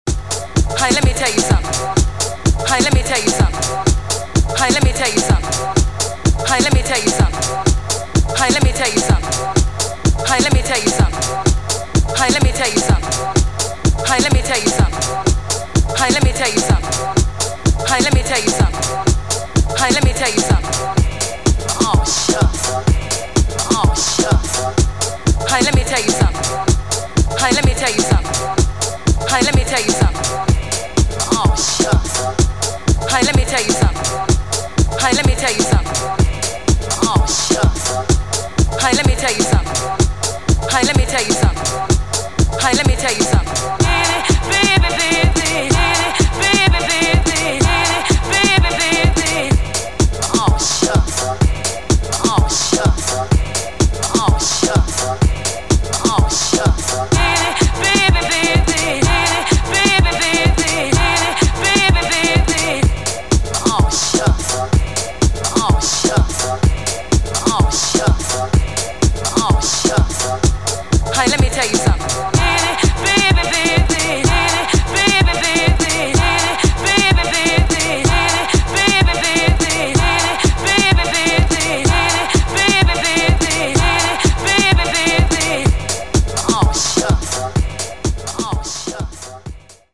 straight from the basement
house music